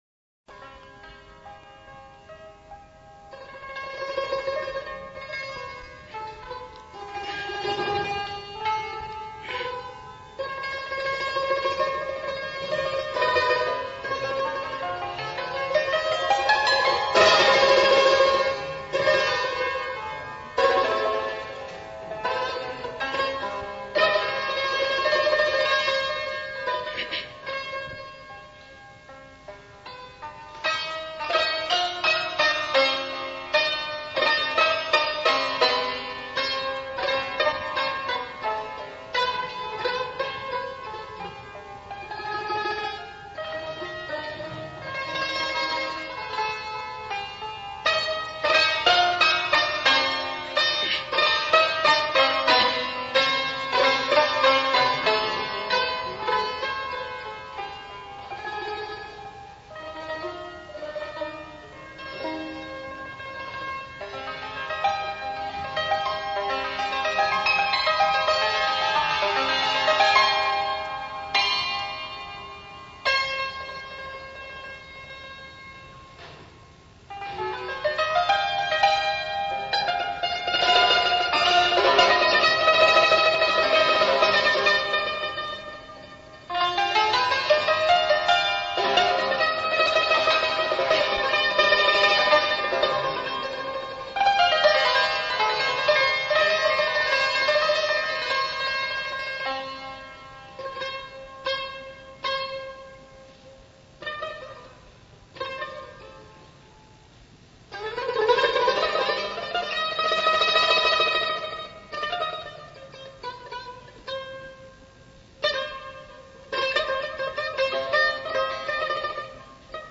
غیر از این سند تصویری با ارزش، تعدادی ضبط‌های پراکنده از هنر تار نوازی او نیز در دست است. بیشتر آنها ابتدا و انتهای منسجمی ندارند و از کیفیت صوتی شفاف، آن طور که امروز می‌شناسیم، عاری هستند.
سنتور
که در مایهٔ بیات اصفهان است.
اجرای آن بسیار خوش صدا و با کوک دقیق و نوانس (نواخت)‌های لطیف و چشم گیر همراه است.